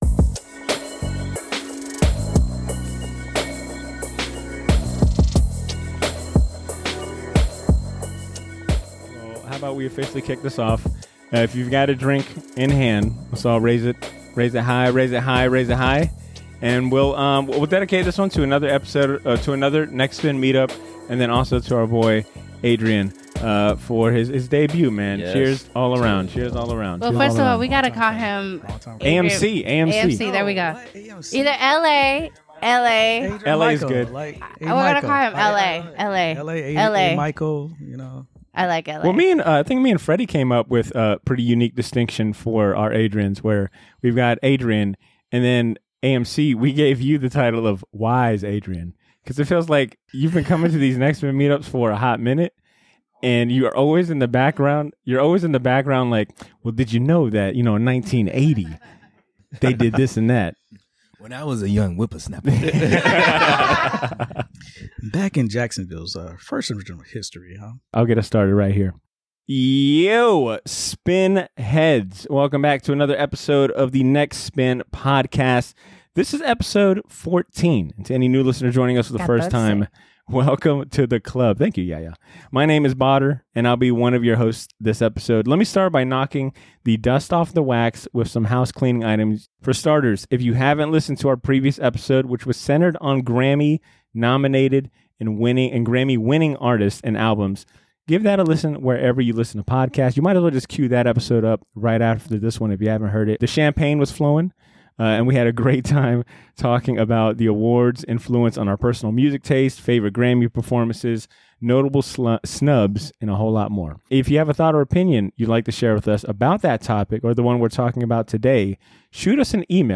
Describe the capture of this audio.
Recorded on May 15th with a full ensemble, this month's theme is centered on 45's!